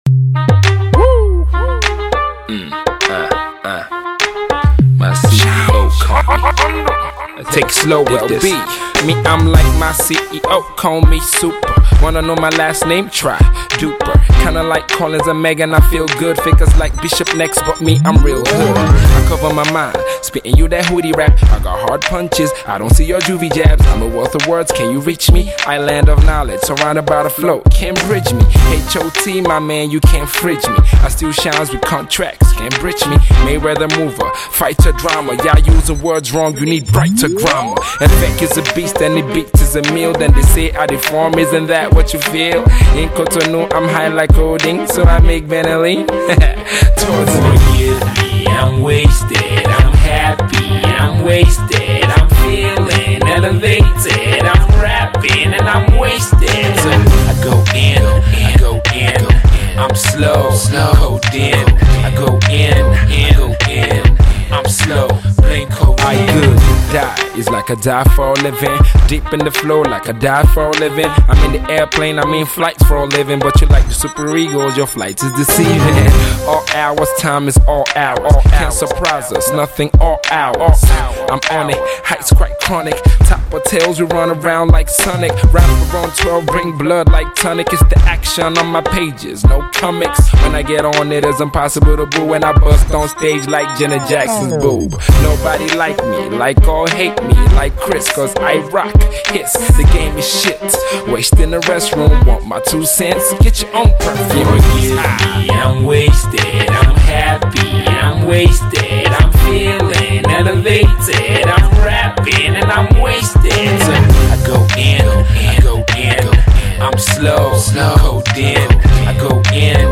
flows and epic wordplay
witty lyrics, funny punch lines